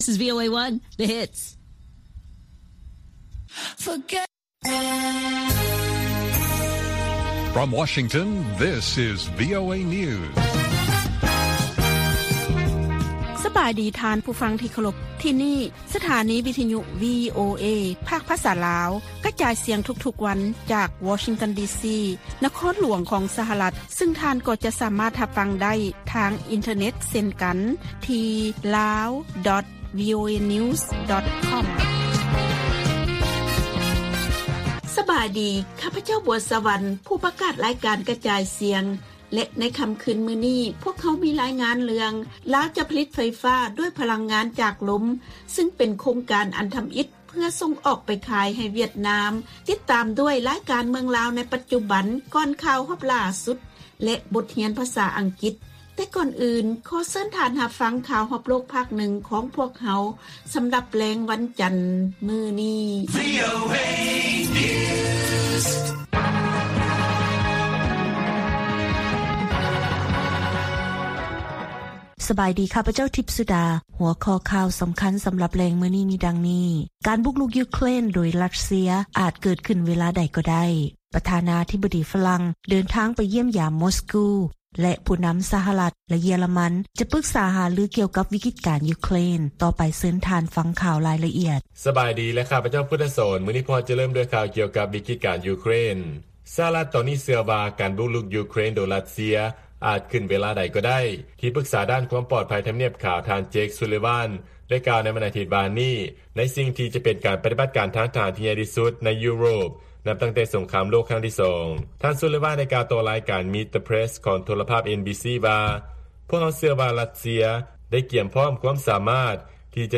ລາຍການກະຈາຍສຽງຂອງວີໂອເອ ລາວ: ການບຸກລຸກ ຢູເຄຣນ ໂດຍ ຣັດເຊຍ ອາດເກີດຂຶ້ນເວລາໃດກໍໄດ້.